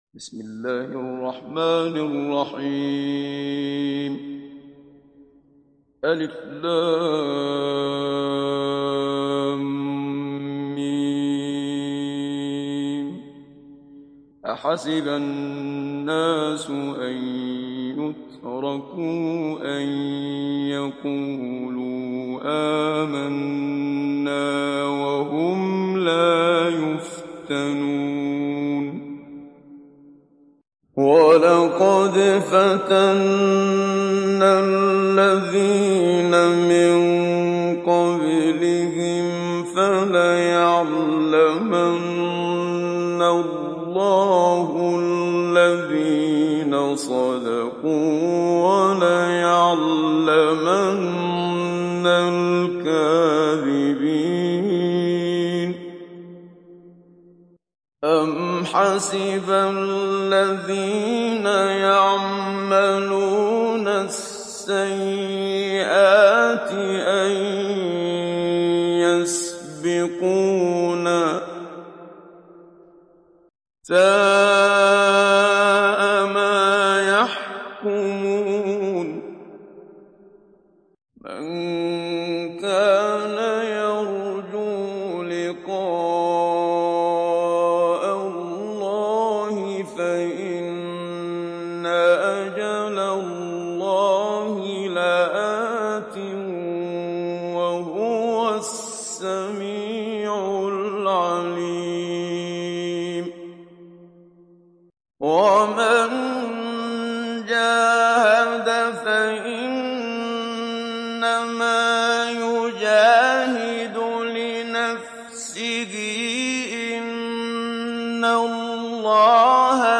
İndir Ankebut Suresi Muhammad Siddiq Minshawi Mujawwad
Hafs an Asim
Mujawwad